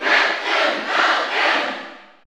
Category: Crowd cheers (SSBU) You cannot overwrite this file.
Captain_Falcon_Cheer_German_SSBU.ogg